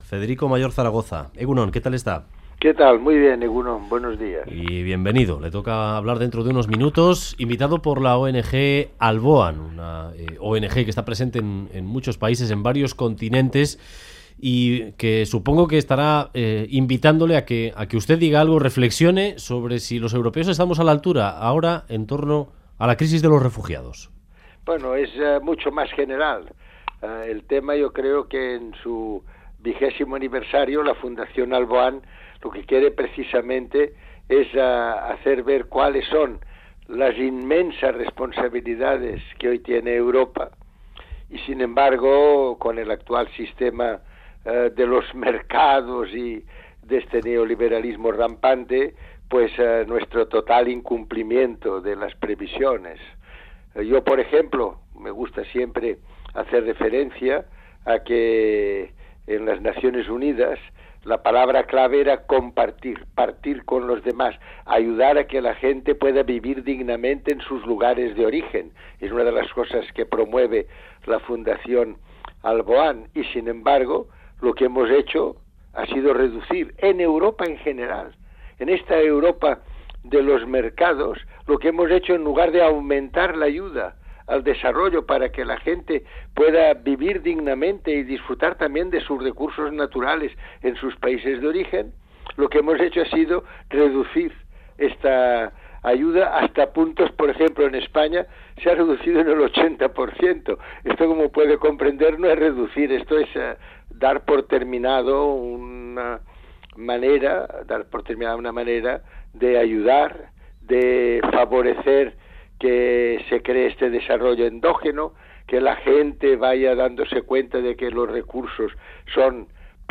Radio Euskadi BOULEVARD 'No hay una Unión Europea real, sólo hay unión monetaria' Última actualización: 04/05/2016 10:34 (UTC+2) En entrevista al Boulevard de Radio Euskadi, el exdirector general de la UNESCO, Federico Mayor Zaragoza, ha lamentado las políticas neoliberales que están haciendo olvidar el concepto de Europa como continente solidario, sede de derechos y libertades. Cree que no hemos conseguido hacer una unión europea política y económica, y que sólo se ha hecho la unión monetaria. Ha denunciado, además, el recorte de ayudas a países desfavorecidos, que en España llega hasta al 80%.